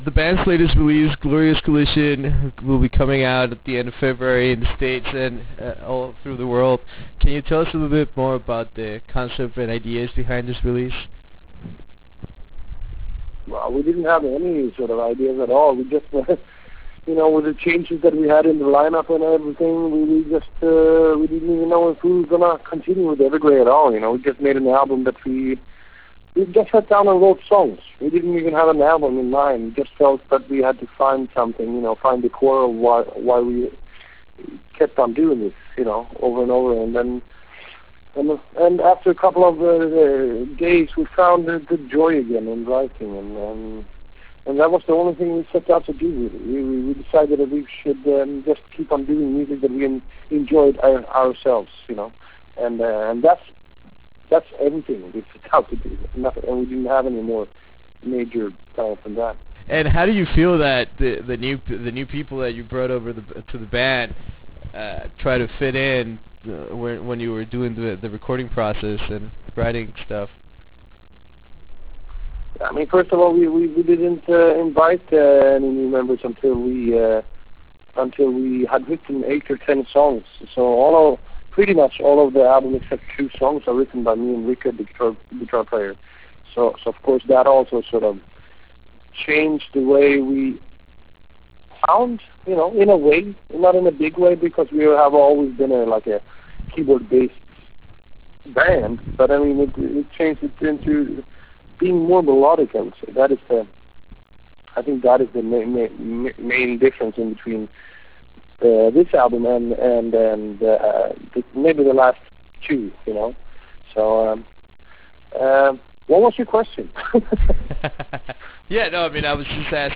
With the impending release of the band’s latest album “Glorious Collision”, we managed to have a phone conversation with the band’s charismatic front-man and mastermind Mr. Tom Englund. In this 15+ minute interview we discuss the new line-up and how the songs in this album came together. We also talk about the darkness (or lack of) behind this very powerful release.